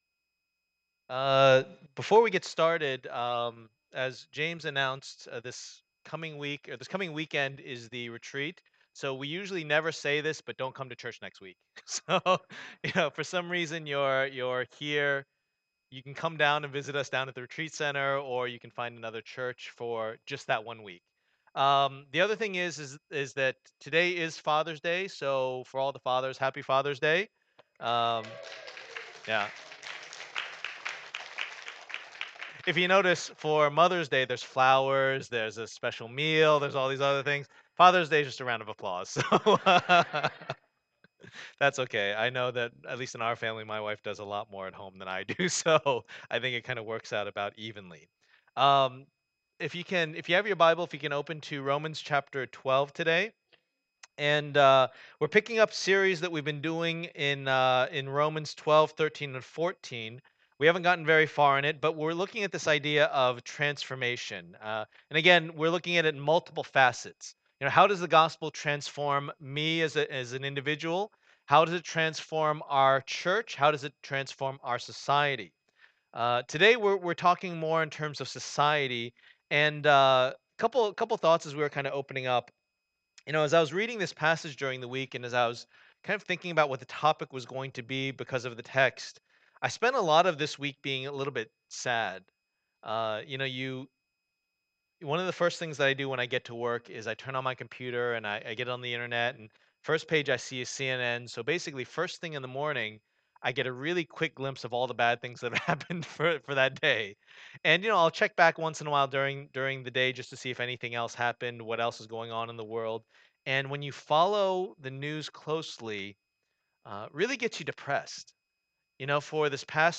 Passage: Romans 12:14-21 Service Type: Lord's Day